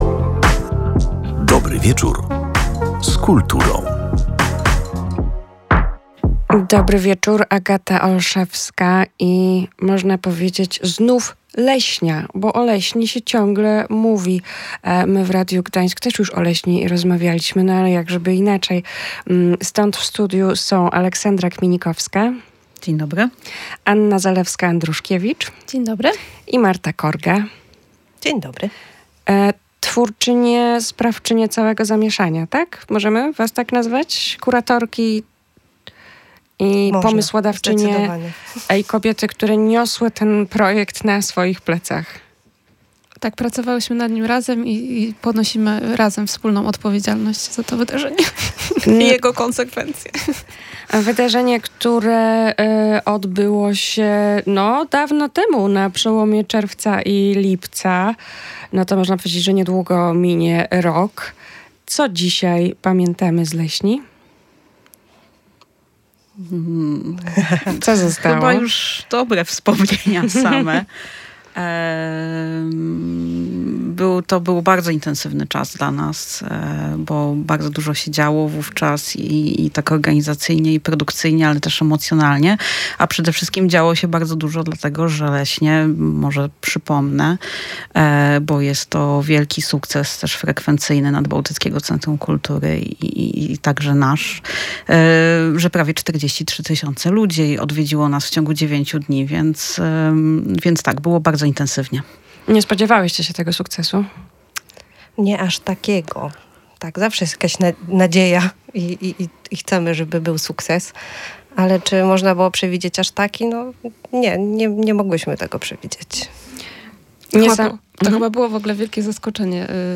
O tym, co w niej znajdziemy, w rozmowie